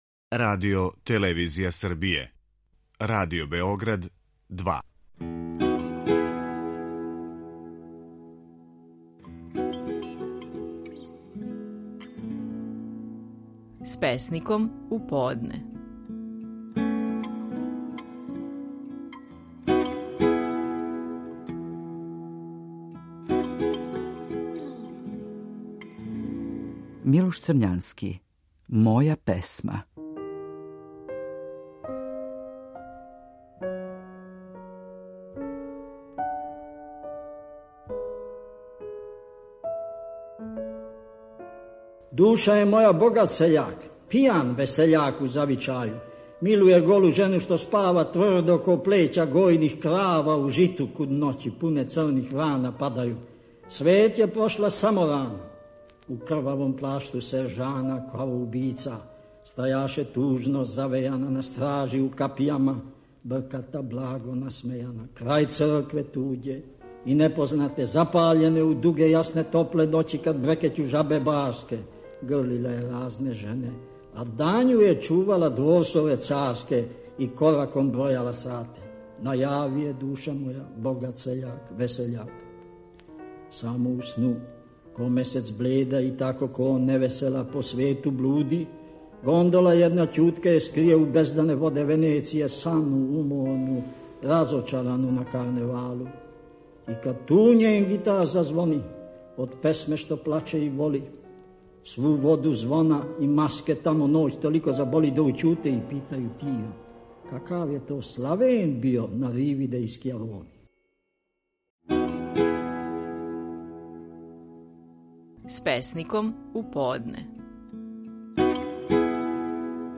Стихови наших најпознатијих песника, у интерпретацији аутора.
Милош Црњански говори: „Моју песму".